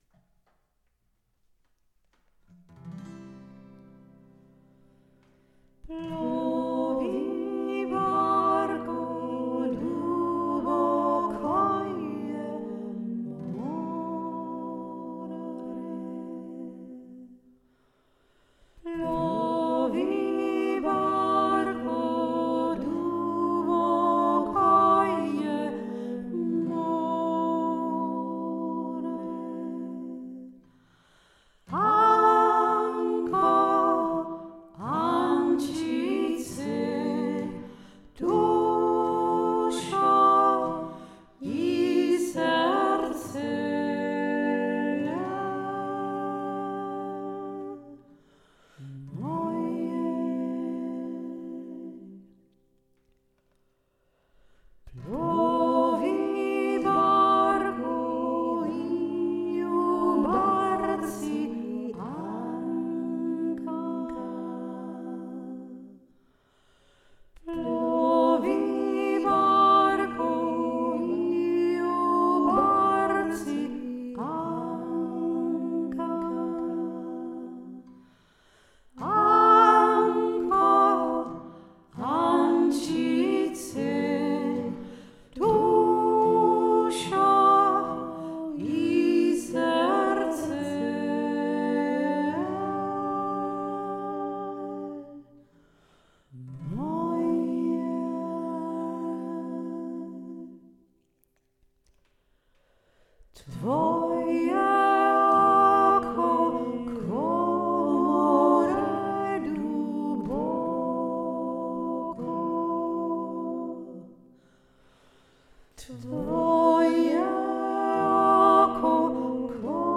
Plovi Barko - kroatisches Liebeslied haben wir nur angesungen
Plovi Barko dreistimmig